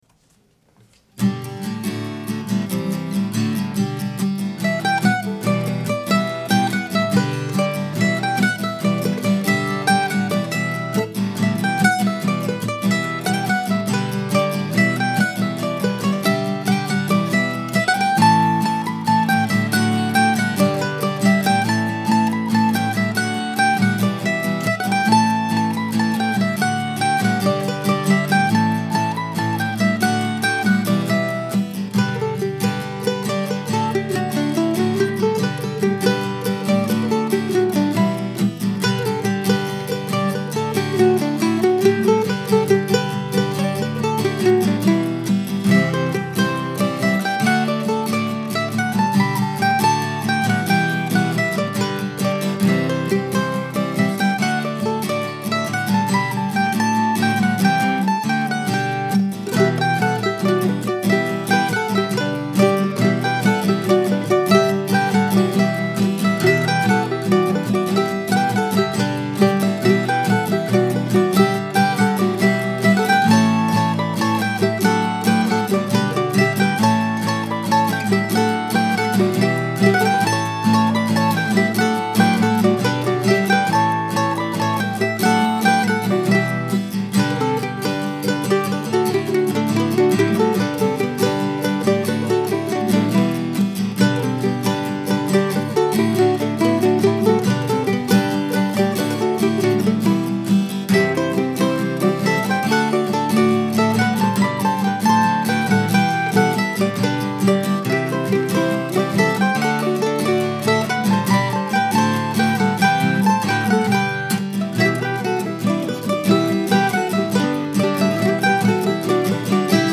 It's a four part tune and was composed in the last few weeks.